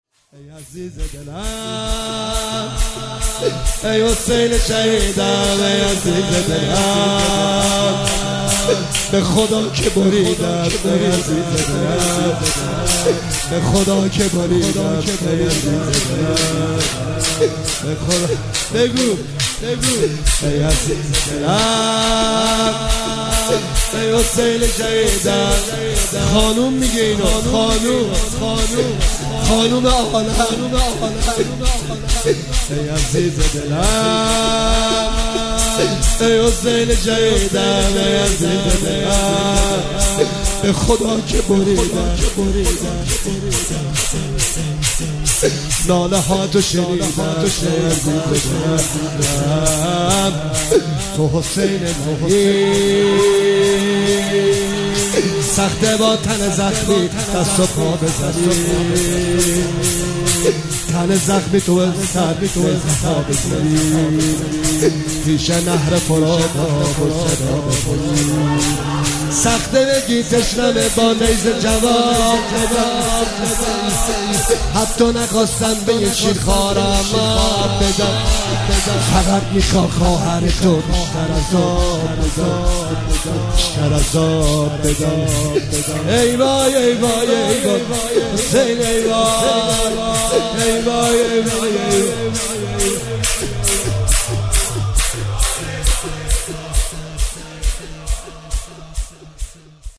هیئت هفتگی 26 اردیبهشت 1404